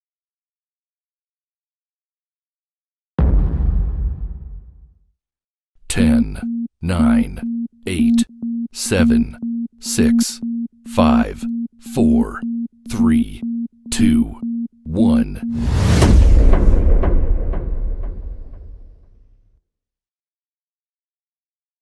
10 Second Timer